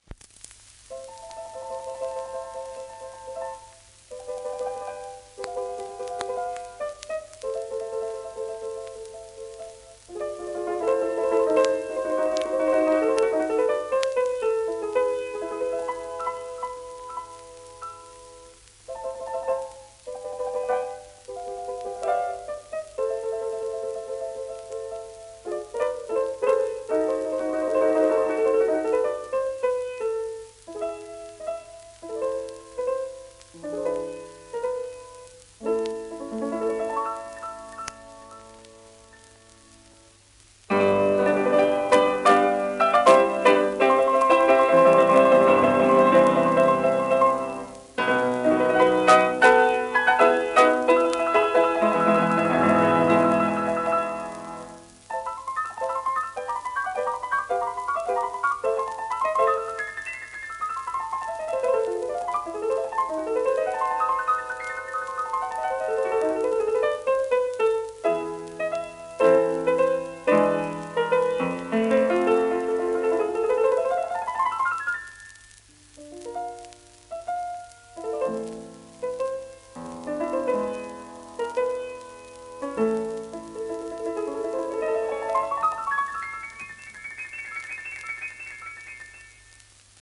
盤質A- *一部に軽度の溝白化
シェルマン アートワークスのSPレコード